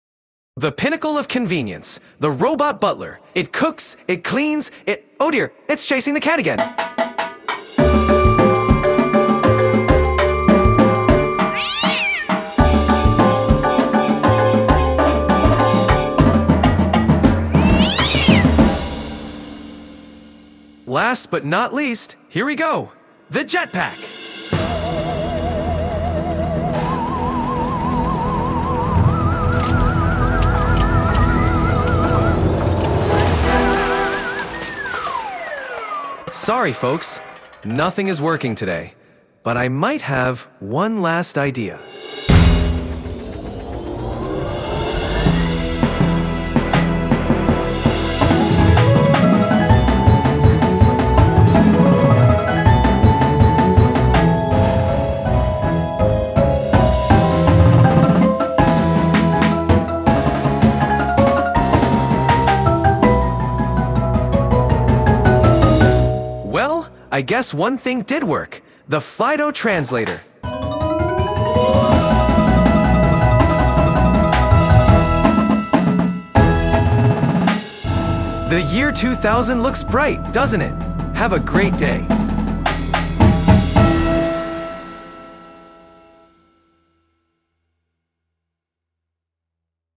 Indoor Percussion Shows
Front Ensemble